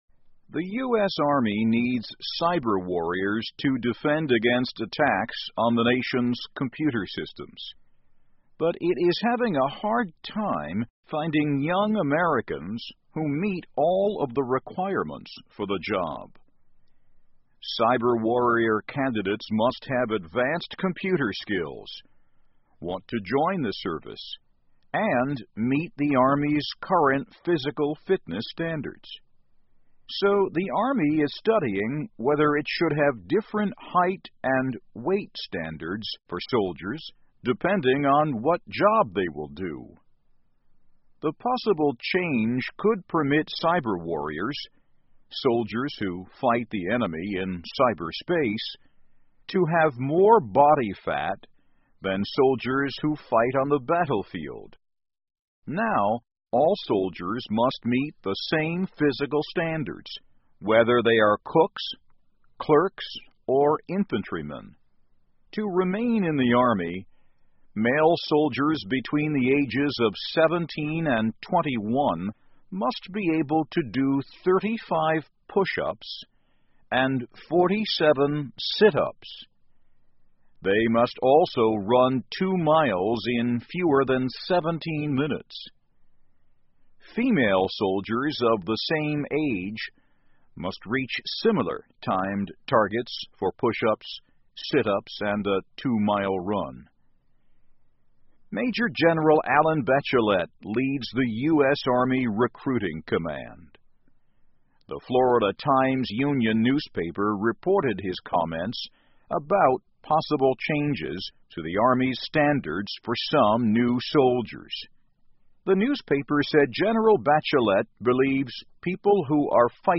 VOA慢速英语2015 美军或修改网军体重规定_ 听力文件下载—在线英语听力室